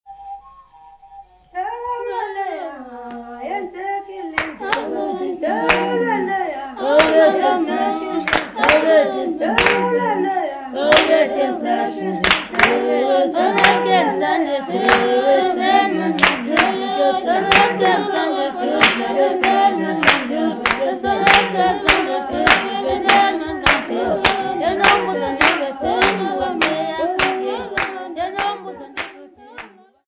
Ladies of Ngqoko
Xhosa Ladies
Folk music--Africa
Field recordings
sound recording-musical
Indigenous music.
7.5 inch reel